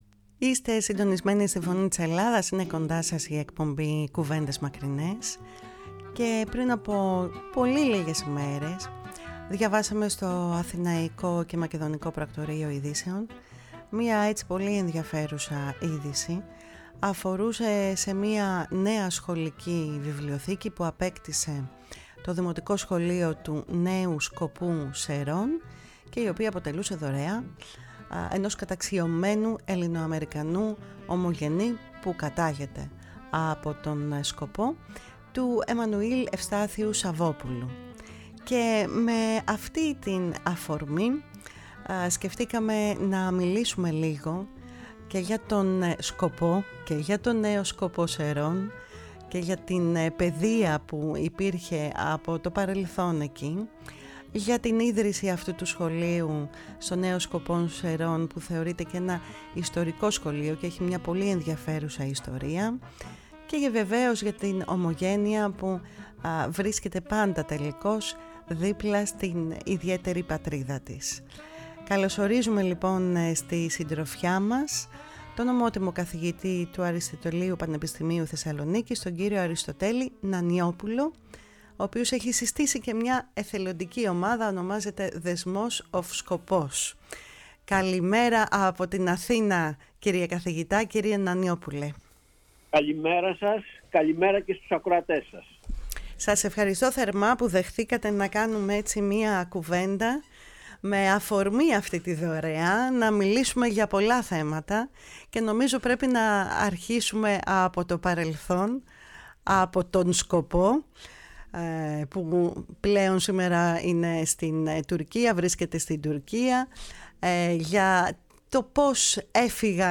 Στην εκπομπή φιλοξενήθηκε τηλεφωνικά